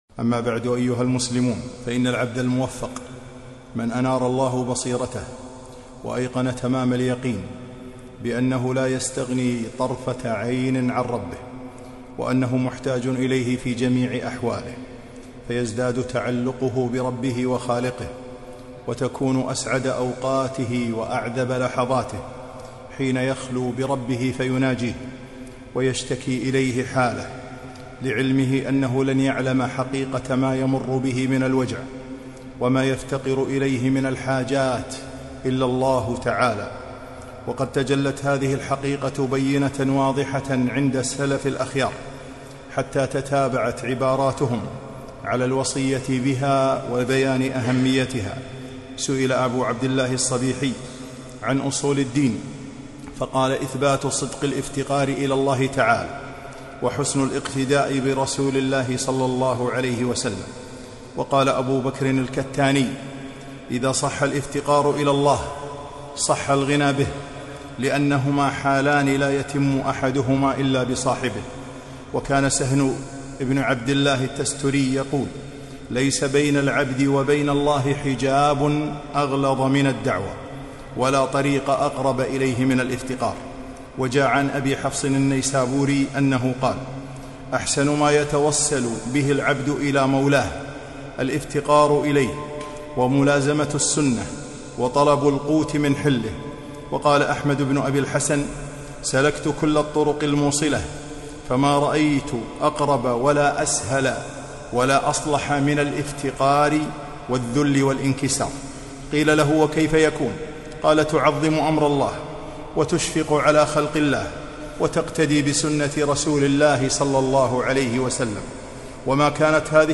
خطبة - الافتقار إلى الله